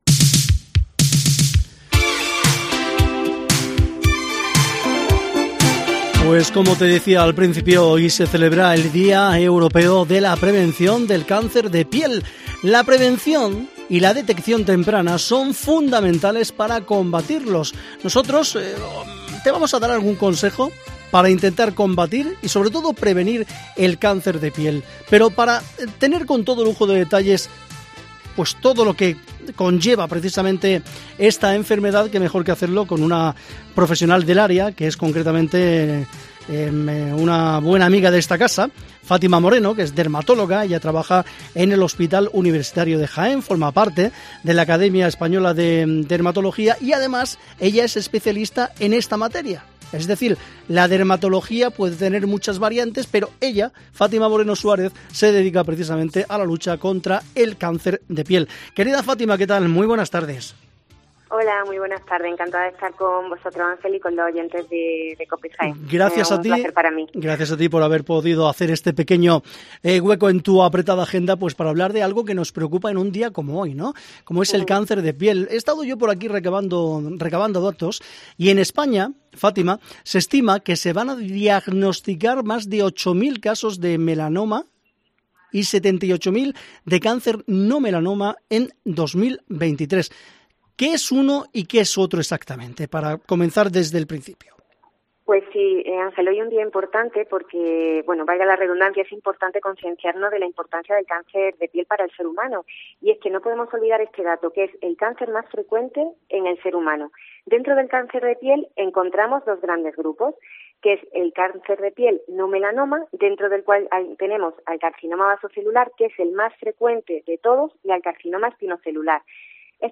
Hoy en COPE charlamos con